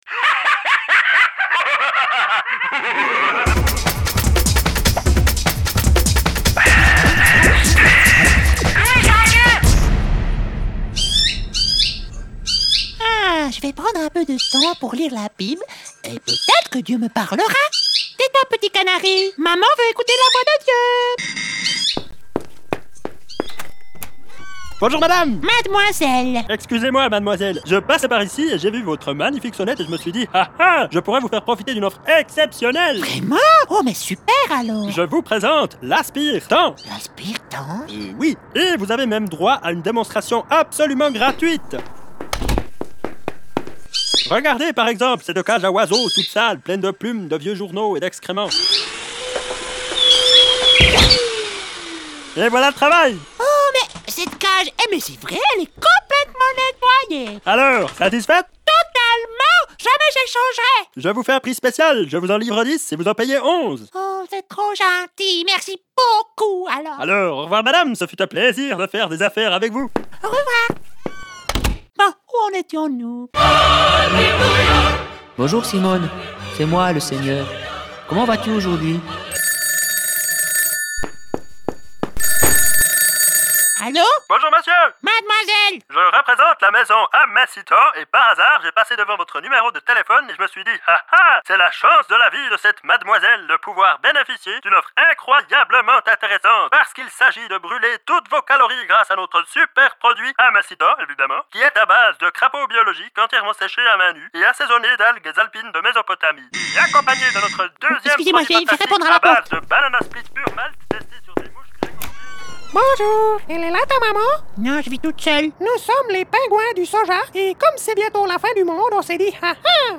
Les sketches :